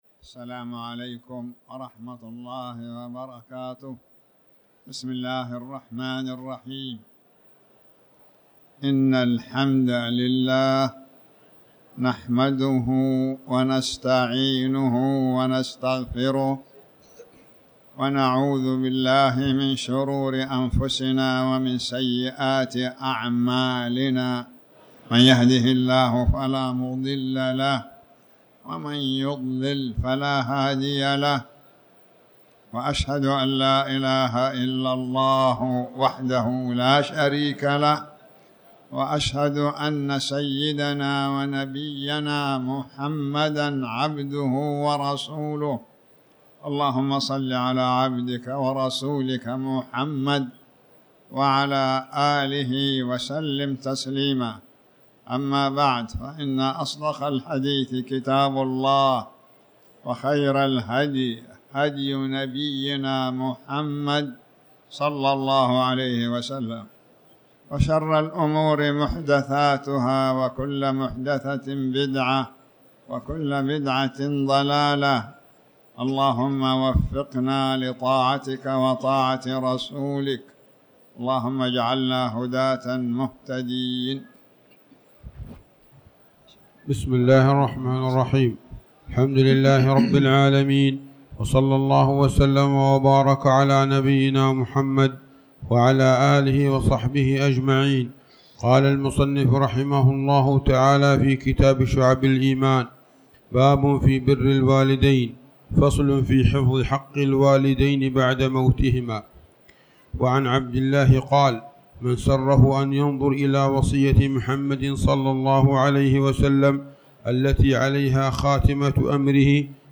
تاريخ النشر ٤ ربيع الثاني ١٤٤٠ هـ المكان: المسجد الحرام الشيخ